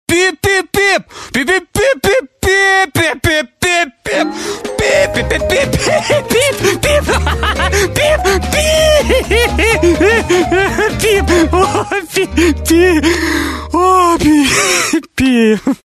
» Пип-пип-пип (чел ржет) Размер: 127 кб